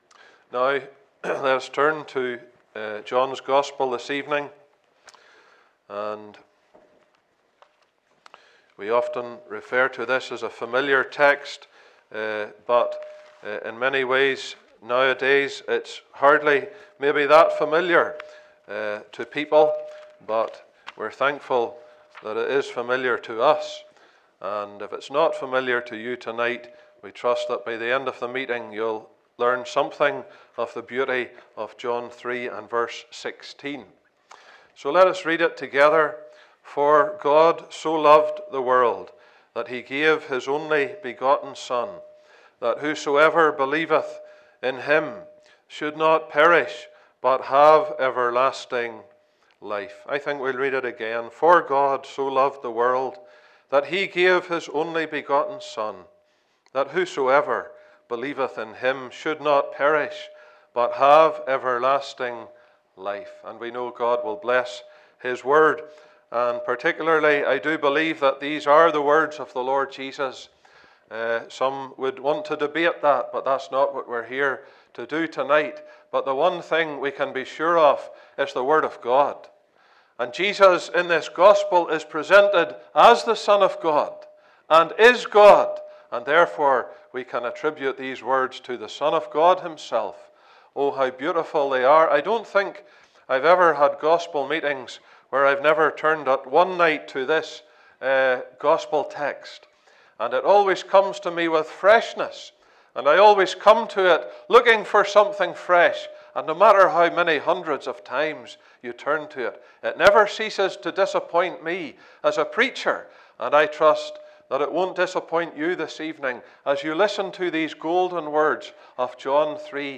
2022 Oxford Gospel Meetings